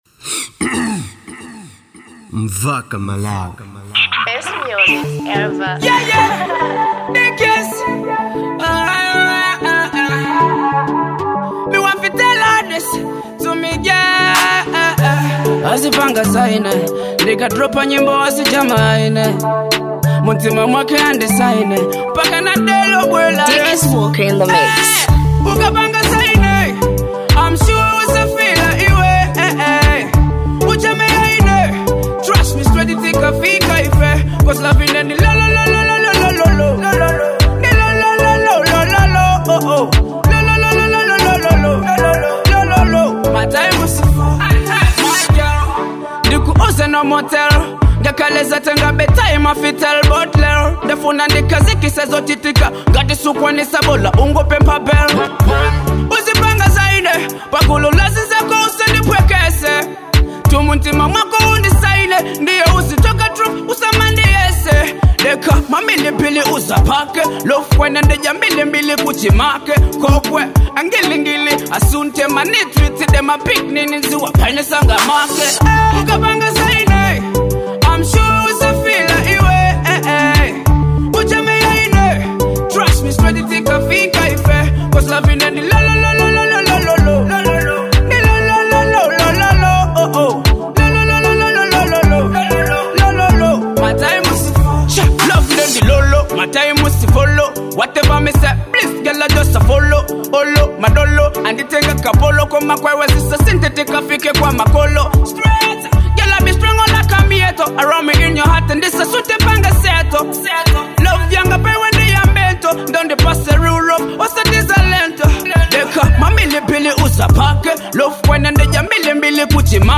type:Dancehall